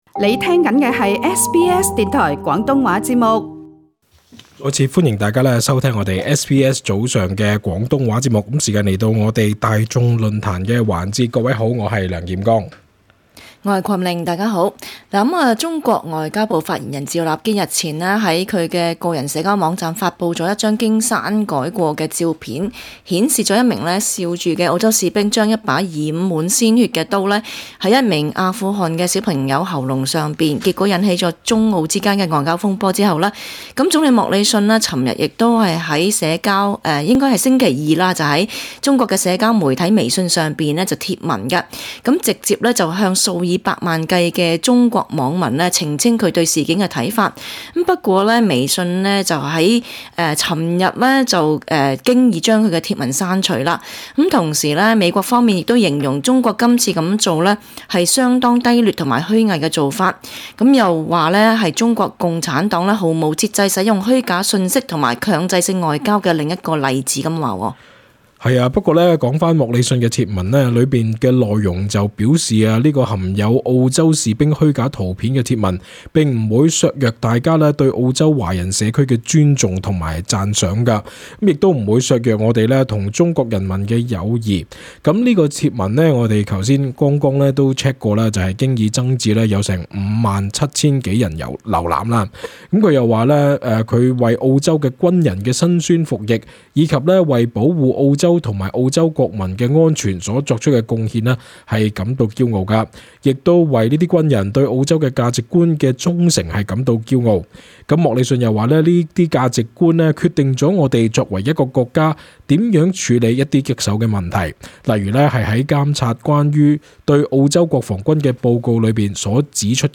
與聽眾傾談